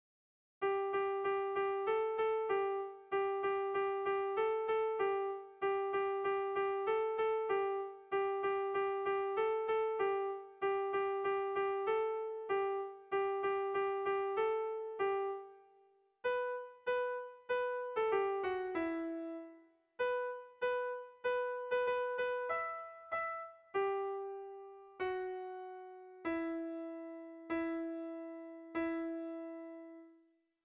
Haurrentzakoa
AAA..